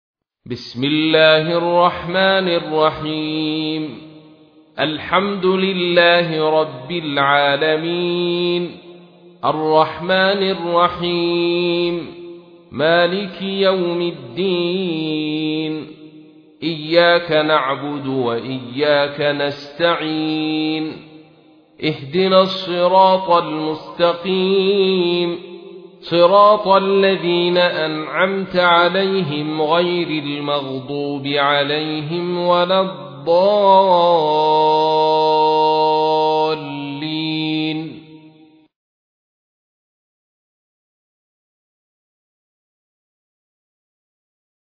تحميل : 1. سورة الفاتحة / القارئ عبد الرشيد صوفي / القرآن الكريم / موقع يا حسين